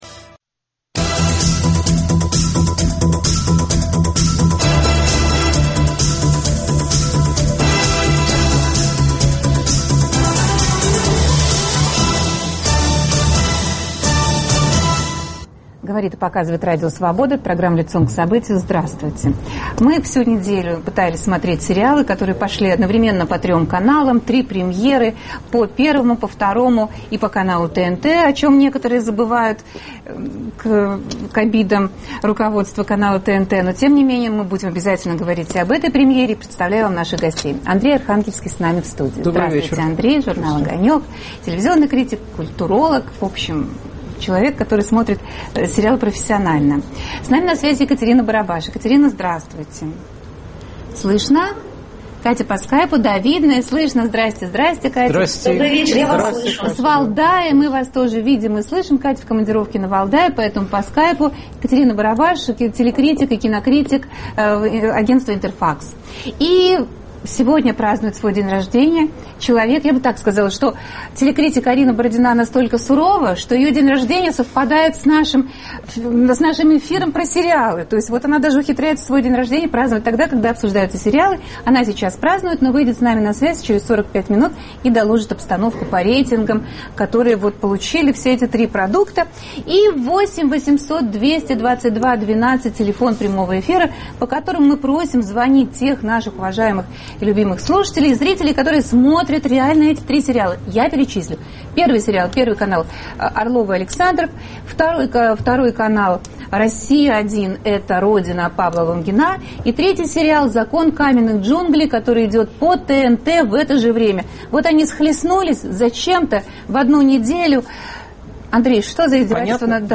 Лобовое столкновение сериалов о Любови Орловой на Первом канале и "Родины" на "России". Какому из них зритель отдает большее предпочтение? Удалась ли Павлу Лунгину адаптация израильской "Родины"? Обсуждают телекритики